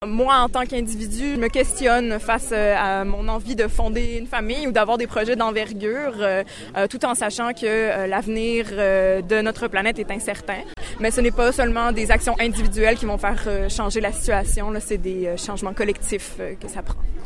Partis de la Place des gens de mer, plusieurs participants à la marche, dont le maire Jonathan Lapierre et le député Joël Arseneau, ont manifesté devant la mairie leurs inquiétudes pour l’avenir de l’humanité :